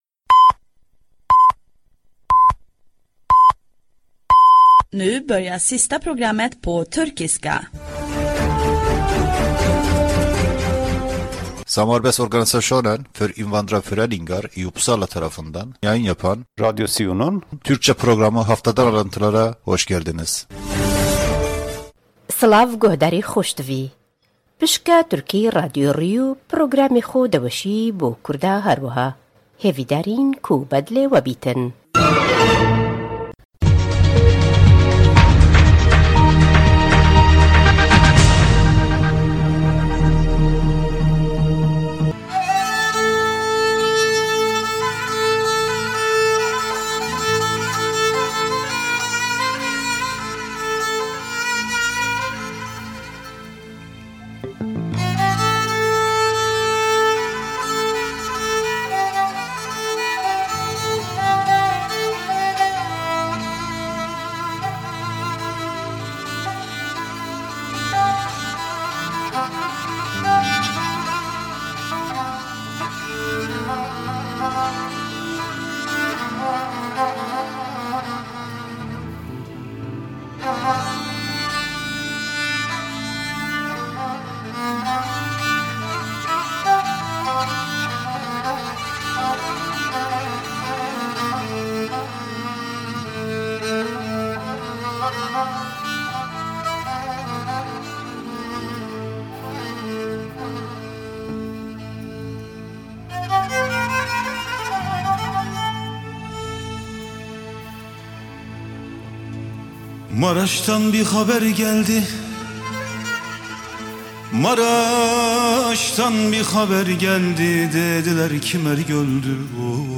Radyo SİU bünyesinde yayın yapan bir programdır. Program; SIU´nun hafta içi faalıyelerıyle ilgili haberler ile lokal haberler ve İsvec genelinden haberleri içerir.